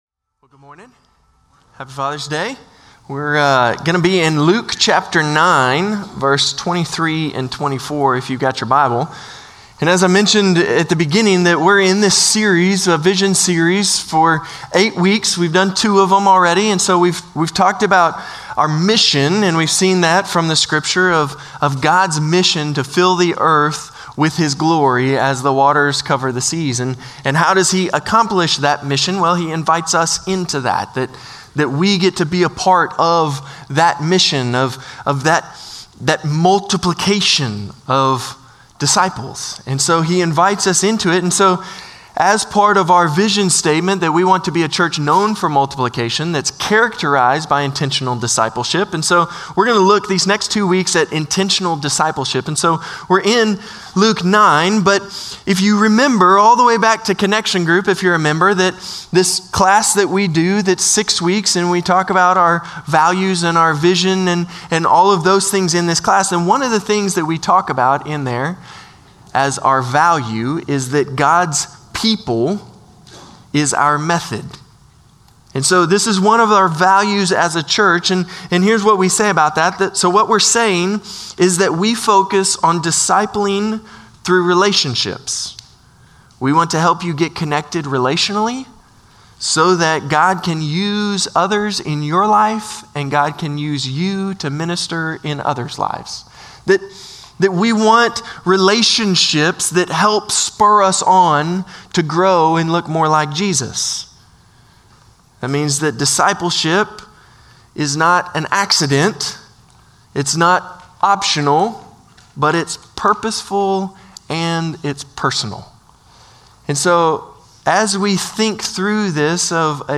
Norris Ferry Sermons June 15, 2025 -- Vision 2025 Week 3 -- Luke 9:23-24 Jun 15 2025 | 00:29:41 1x 00:00 / 00:29:41 Subscribe Share Spotify RSS Feed Share Link Embed